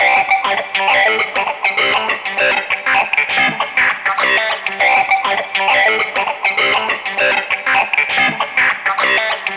Loops guitares rythmique- 100bpm 3
Guitare rythmique 52